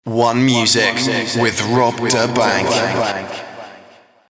'OneMusic with Rob da Bank' voice (with effects)
voiceeffects.mp3